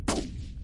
180081 Rilfe Suppressor 01
描述：Screwing on a suppressor of a .308 rifle
标签： Suppressor Rifle Silencer gun Screwing OWI
声道立体声